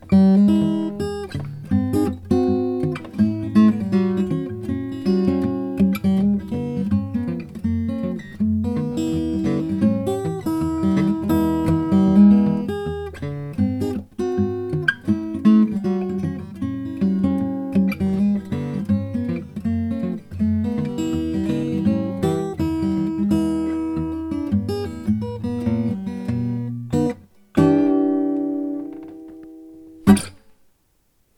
Jumbo Cedar/Padouk with fallaway cutaway NEW SOUNDS!
Cedar top
Padouk Back/sides
Recorded with condensor microphones, without effects
The guitar sounds like it has a great deep voice.